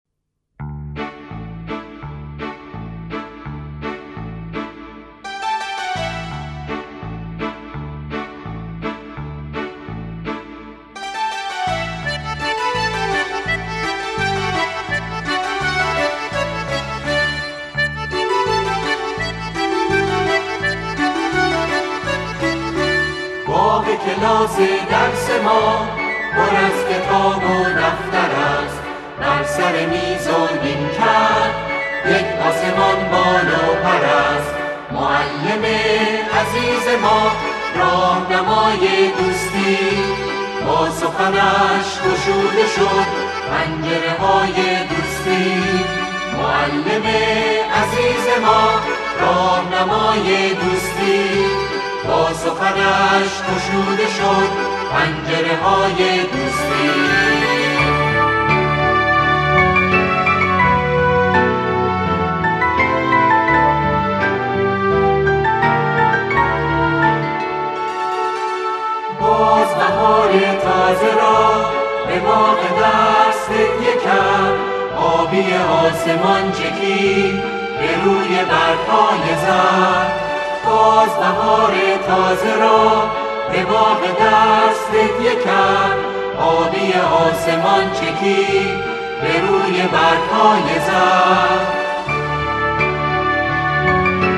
با اجرای گروه کر تهیه شده است.
اعضای گروه شعری را با موضوع مدرسه و دوستی همخوانی می‌کنند.